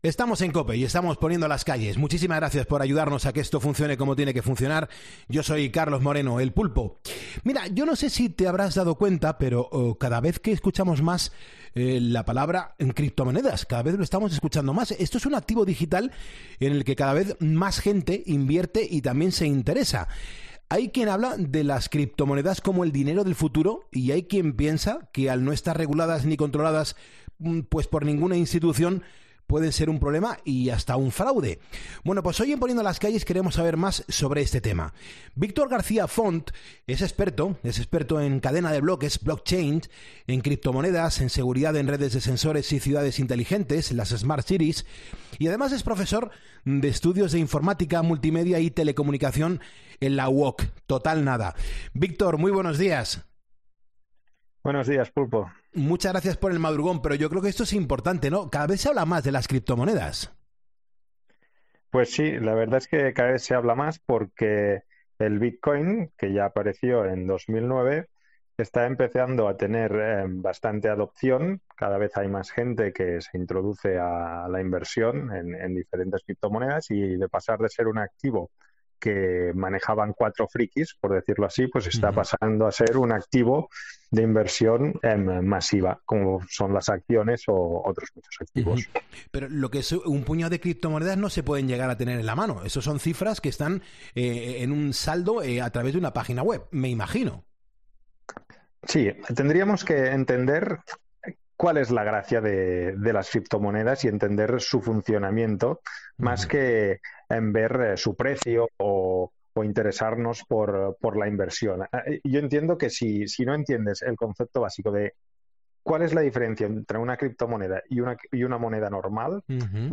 AUDIO: Dos especialistas en criptomonedas y finanzas analizan en 'Poniendo las Calles' las ventajas y los inconvenientes de las criptomonedas